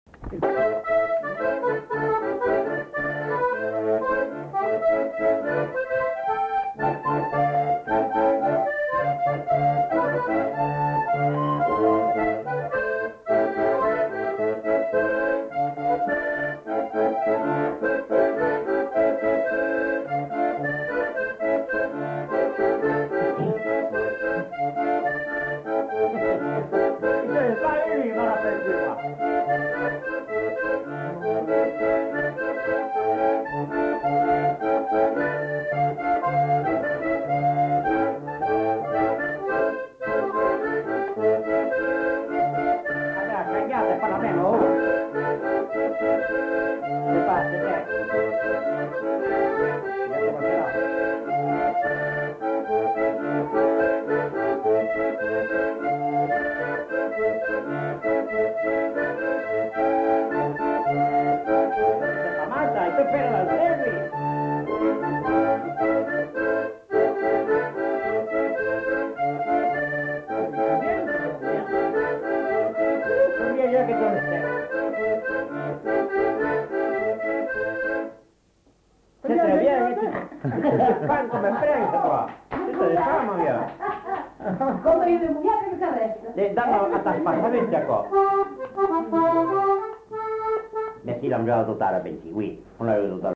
Aire culturelle : Lugues
Lieu : Pindères
Genre : morceau instrumental
Instrument de musique : accordéon diatonique
Danse : polka